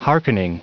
Prononciation du mot hearkening en anglais (fichier audio)
Prononciation du mot : hearkening